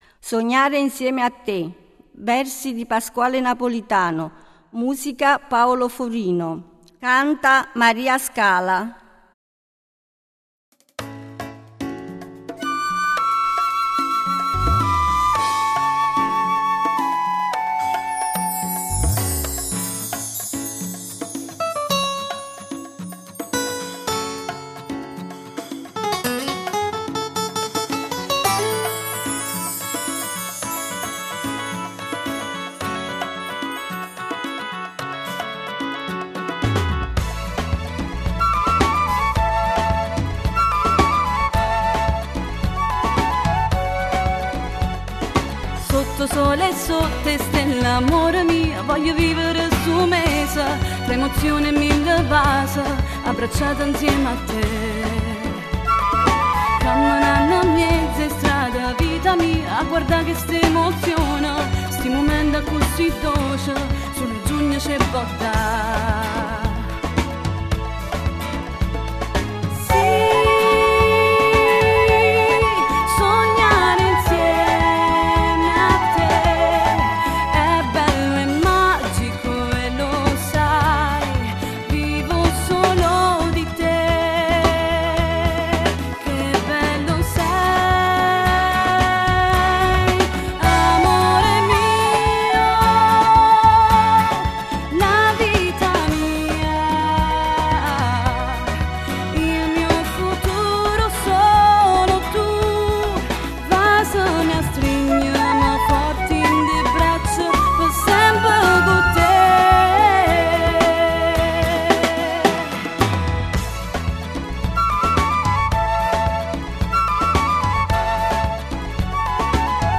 Giglio del Panettiere 2009